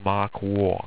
Pronounced
MARK WOR